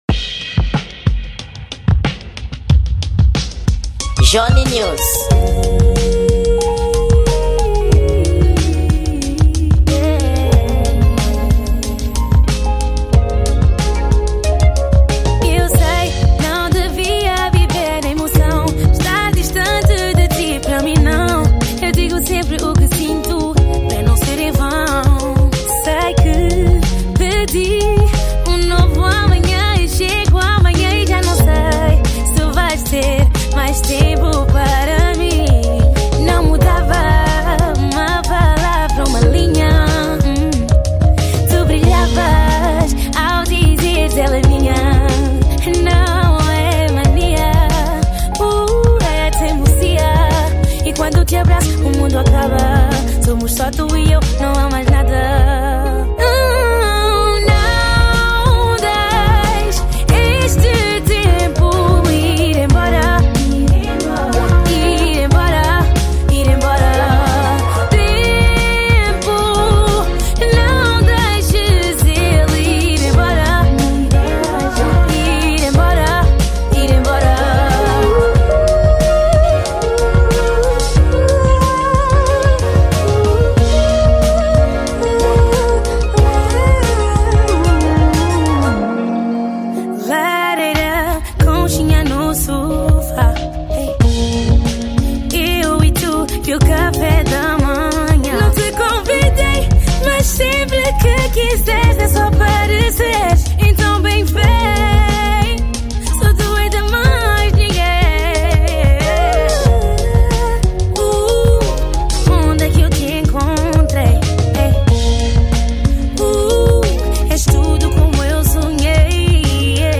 Gênero: R&B